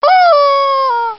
Rubber Chicken Sound Buttons
Rubber Chicken